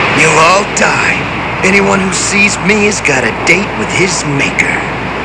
YAY! we all love da sound clips! i gots some of da clips of Duo from da dubbed version of GW. subbed would b better but Its DUO so i dont care! click on the clip u wanna hear and enjoy!